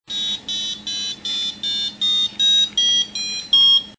Dix sons diff�rents appelables avec TONE n Pour entendre les sons produits par ce programme, cliquez ici!
hp41_tone.mp3